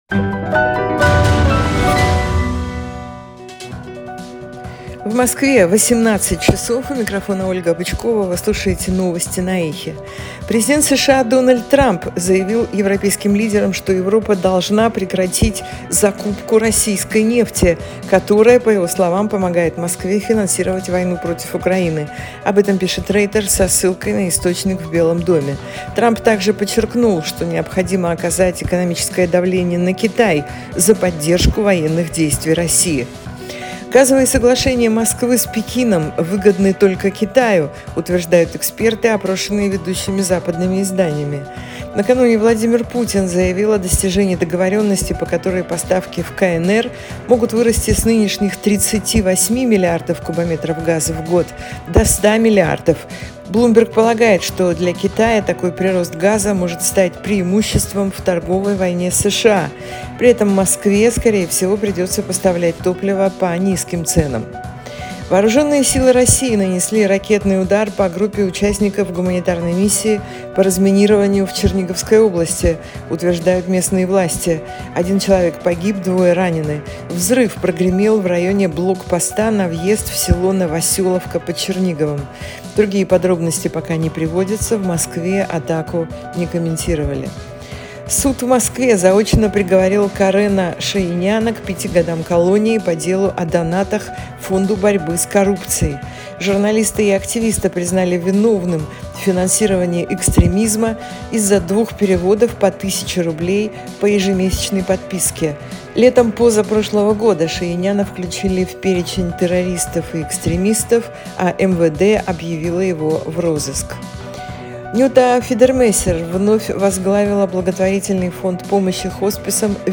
Слушайте свежий выпуск новостей «Эха»